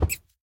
rabbit_hurt1.ogg